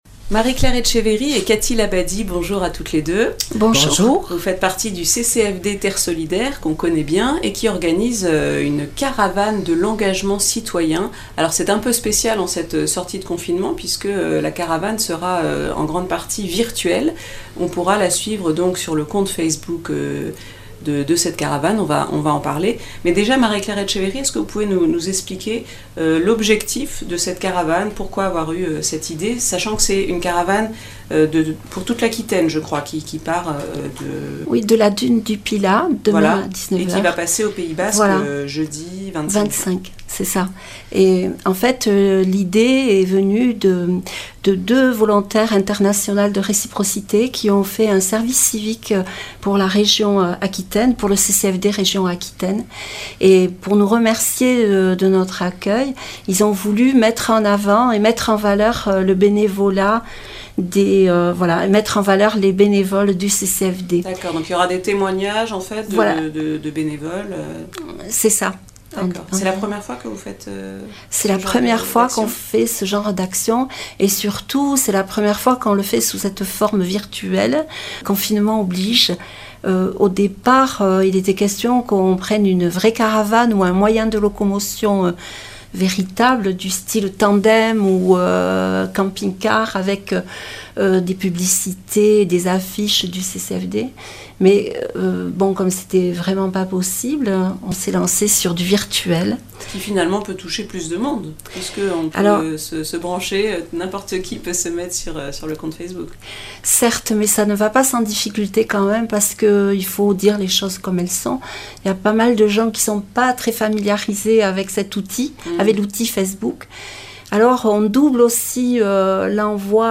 Accueil \ Emissions \ Infos \ Interviews et reportages \ La caravane de l’engagement citoyen du CCFD-Terre Solidaire le 25 juin à (...)